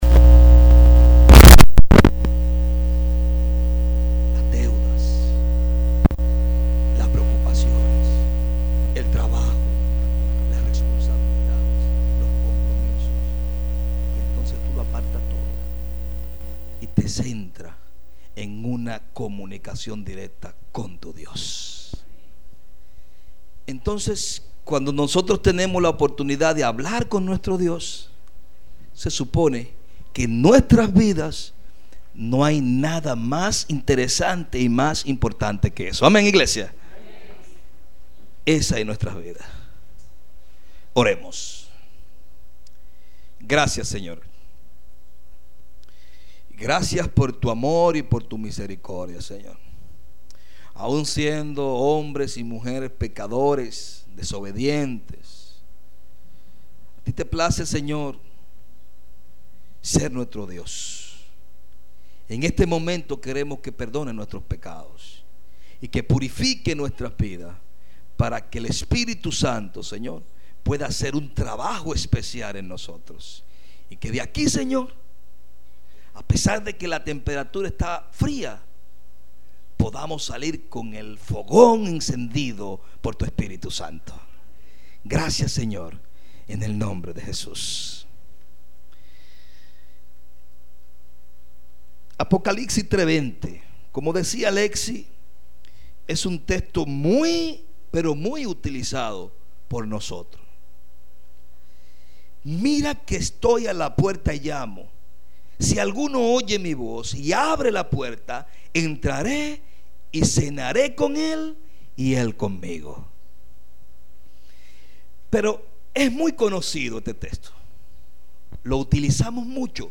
culto divino
Culto al Divino en la iglesia La fe , en Villa alacrán la romana.